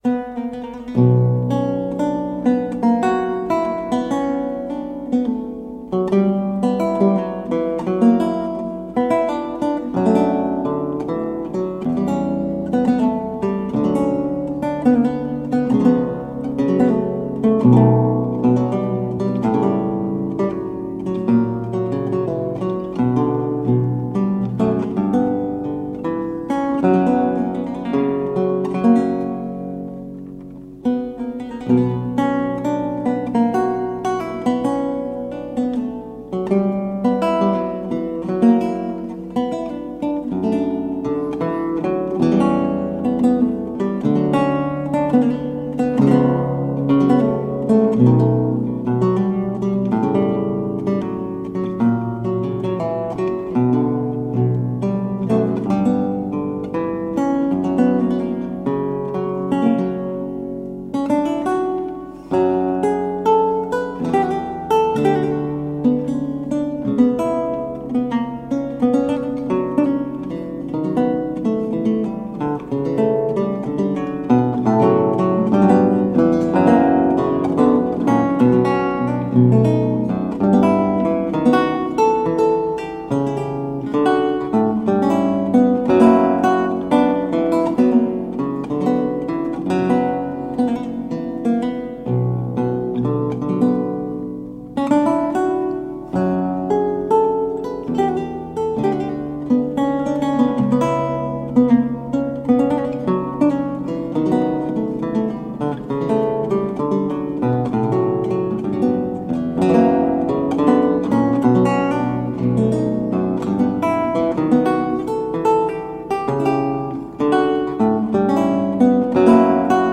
A marvelous classical spiral of lute sounds.
Classical, Baroque, Instrumental
Lute